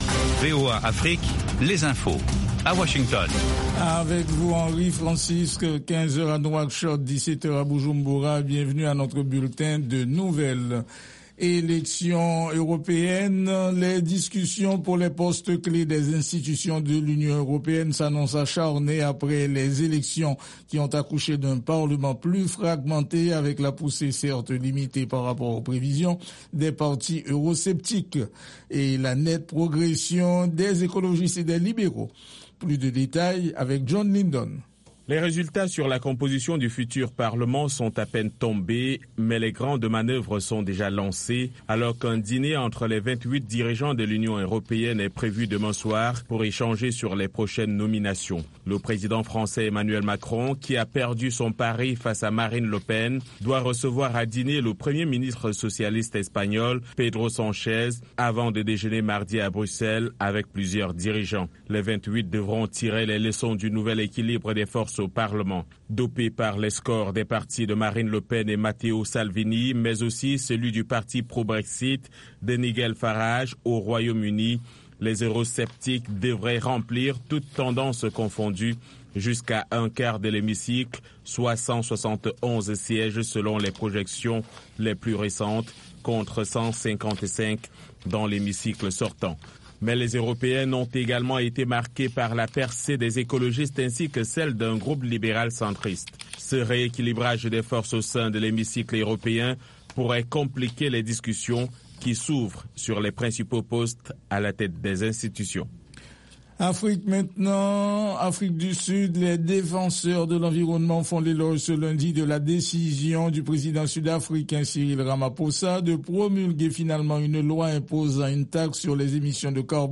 Basango na VOA Lingala